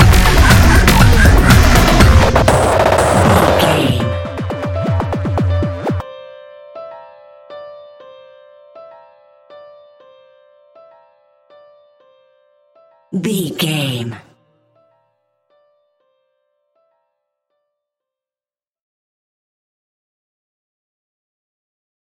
Epic / Action
Fast paced
Atonal
intense
energetic
driving
aggressive
dark
piano
synthesiser
drum machine
breakbeat
synth bass